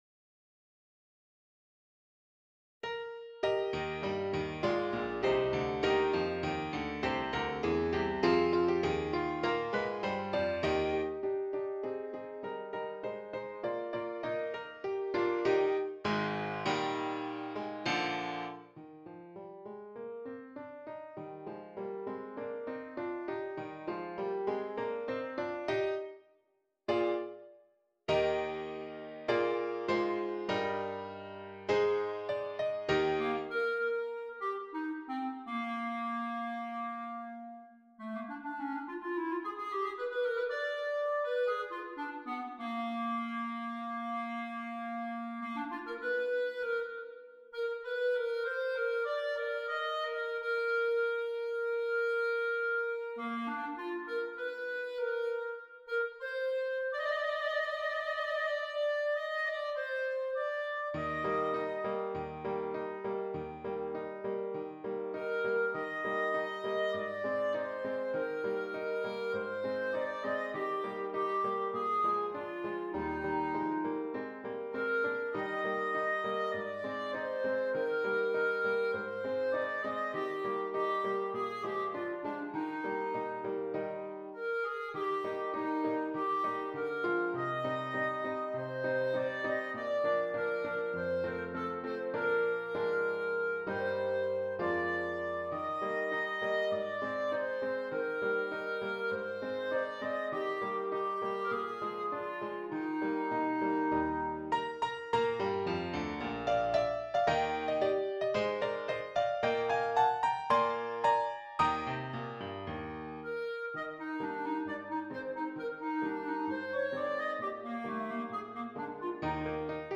Clarinet and Keyboard
Traditional